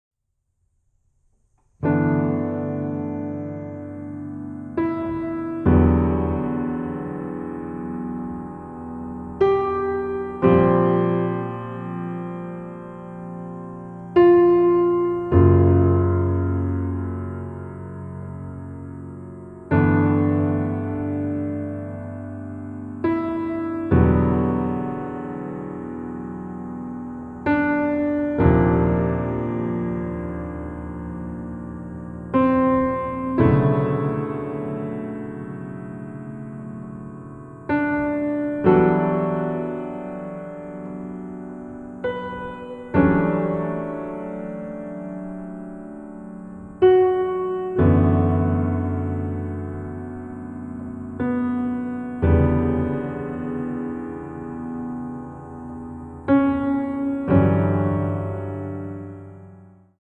空間を意識させる旋律と響きの妙が強く印象に残る傑作です！